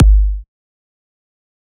EDM Kick 9.wav